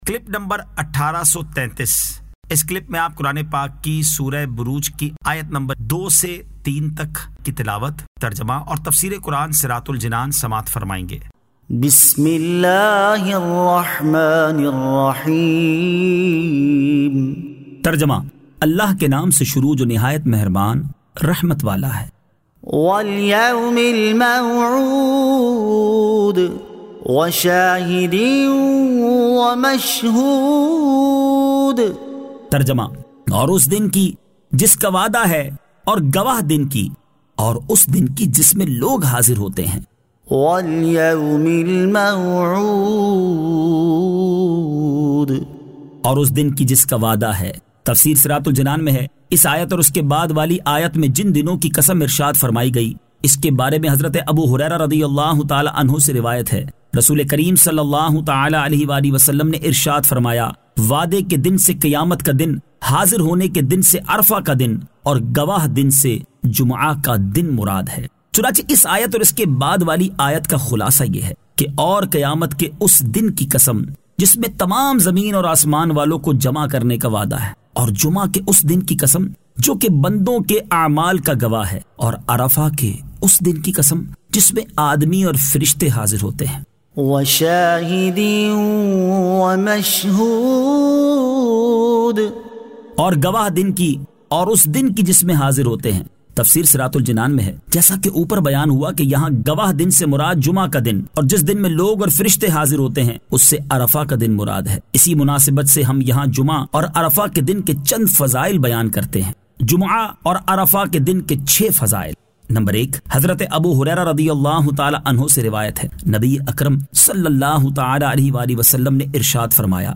Surah Al-Burooj 02 To 03 Tilawat , Tarjama , Tafseer